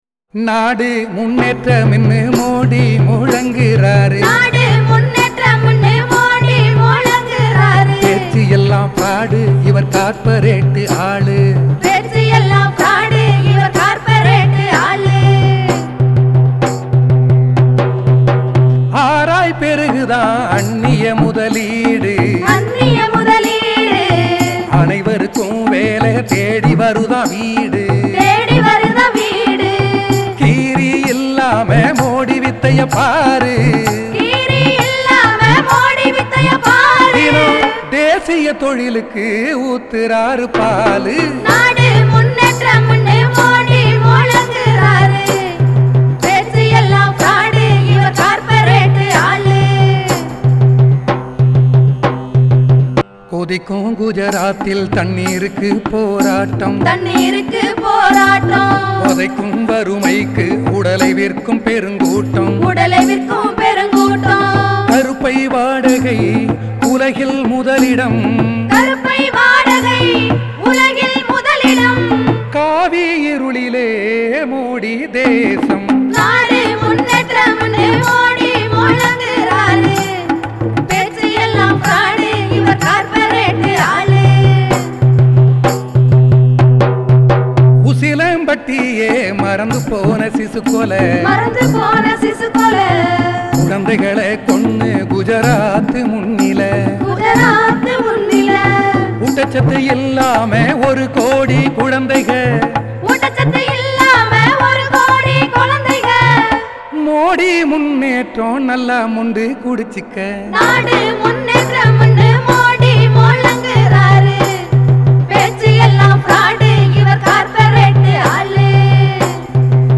pala-song-opposing-modi.mp3